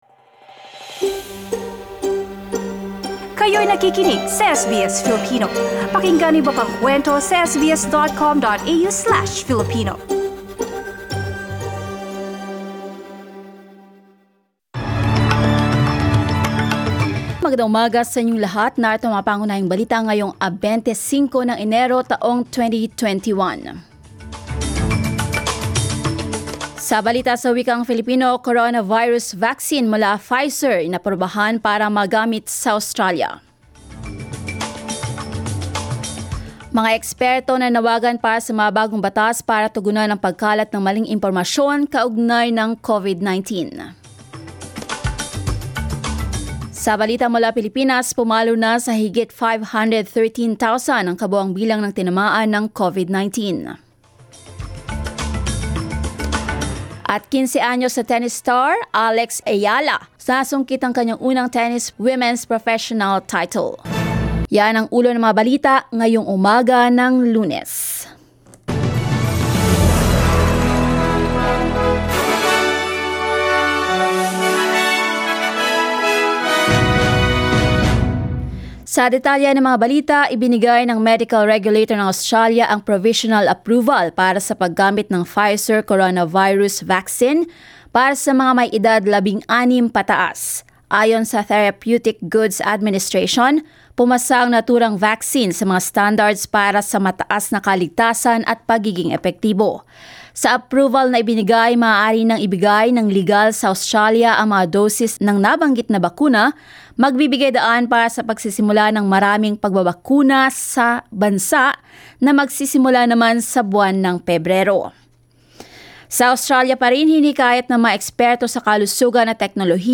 SBS News in Filipino, Monday 25 January